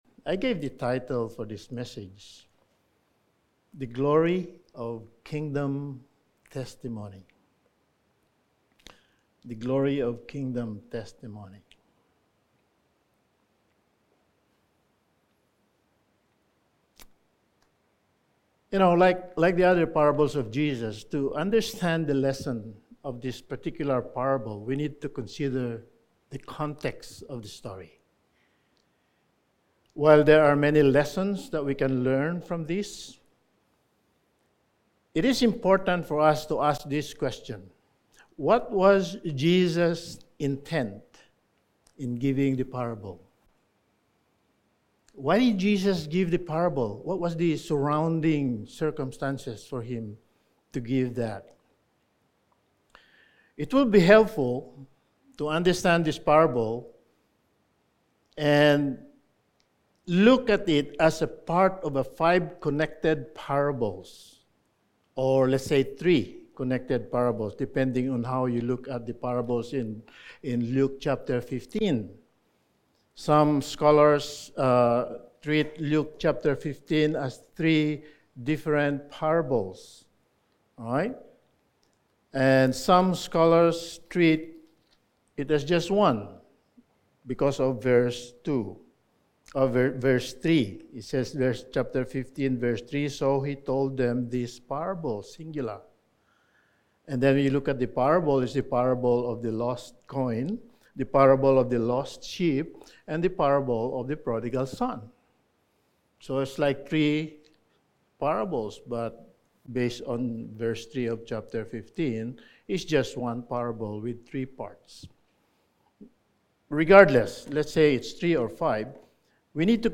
Sermon
Passage: Luke 16:19-31 Service Type: Sunday Evening Sermon 15 « Faith and Providence Exhortations for a Healthy Church